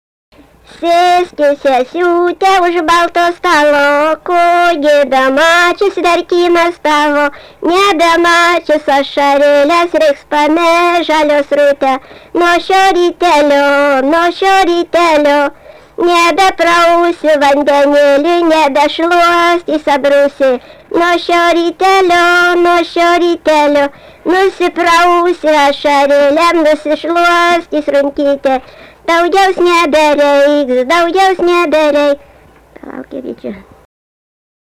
daina, vestuvių
Ryžiškė
vokalinis